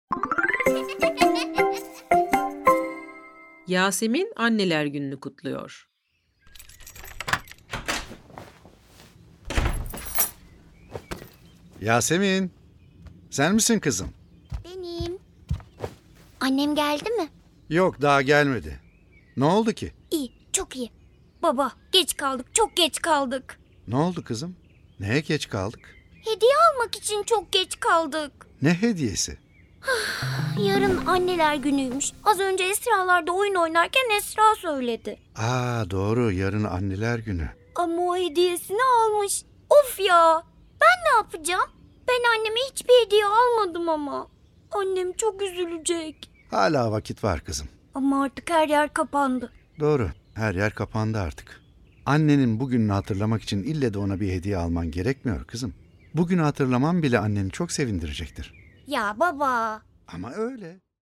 Yasemin Anneler Gününü Kutluyor Tiyatrosu